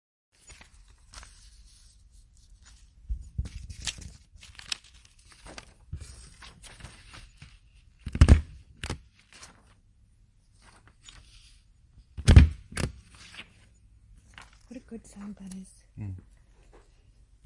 描述：A hand hitting paper
标签： papers hit paper
声道立体声